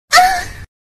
Anime Ahh Sound Effect Free Download